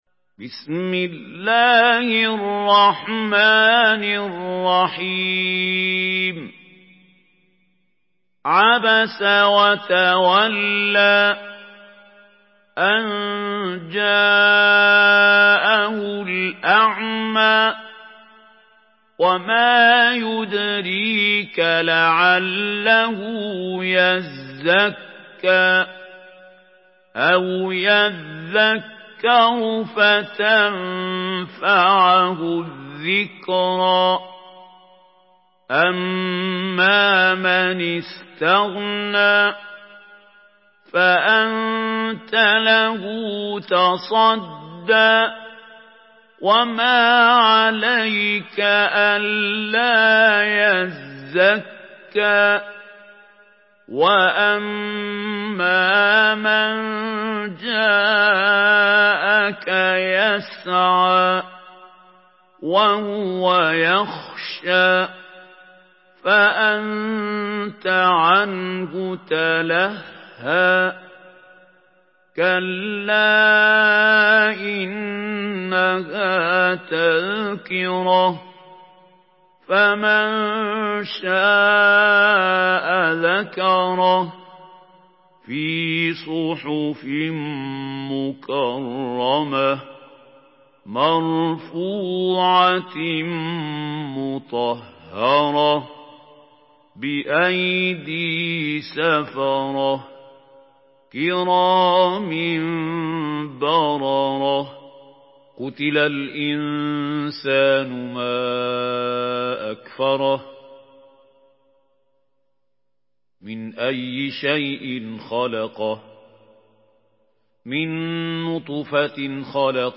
Surah Abasa MP3 in the Voice of Mahmoud Khalil Al-Hussary in Hafs Narration
Murattal Hafs An Asim